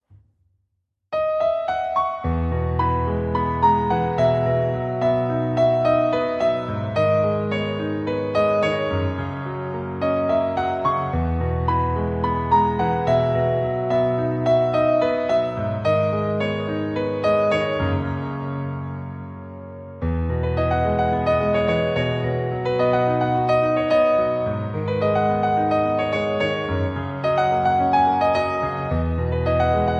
• Key: E Major
• Instruments: Piano solo
• Genre: Pop, Rock